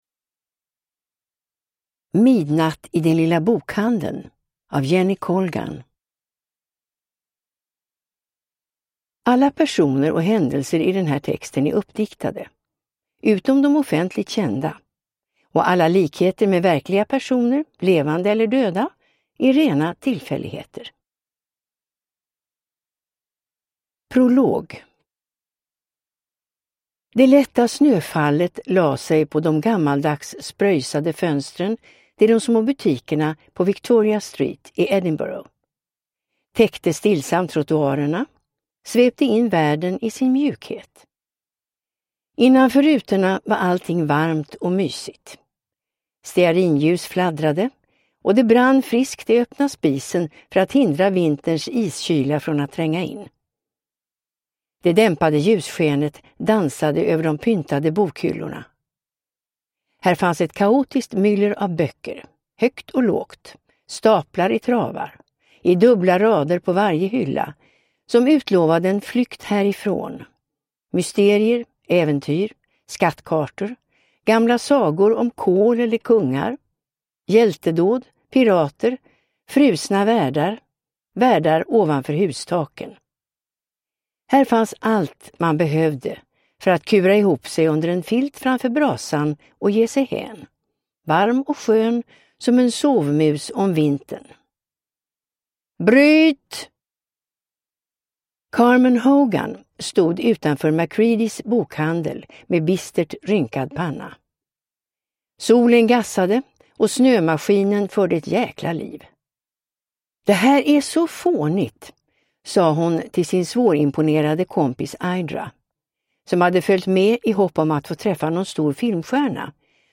Midnatt i den lilla bokhandeln – Ljudbok